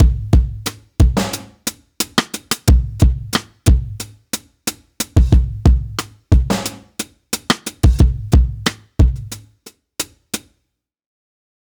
Unison Jazz - 9 - 90bpm.wav